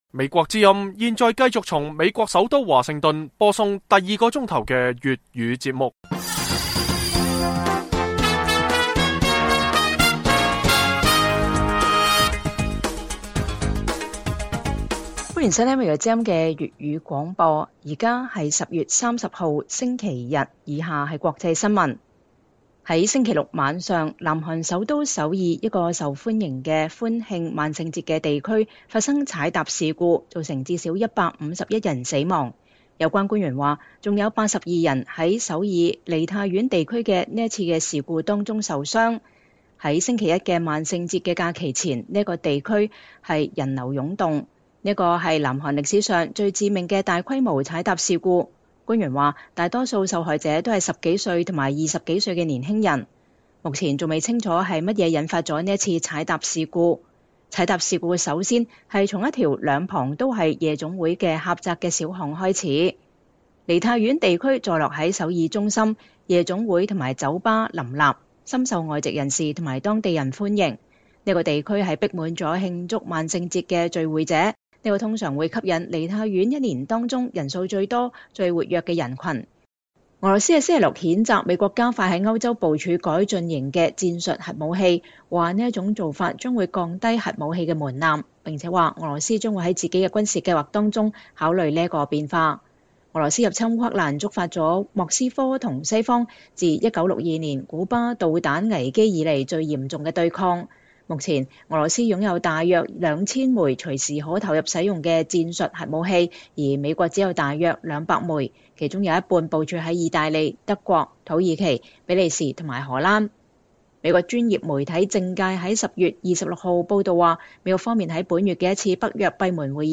粵語新聞 晚上10-11點: 美國最新戰術核彈提前部署歐洲，莫斯科稱這會降低核武門檻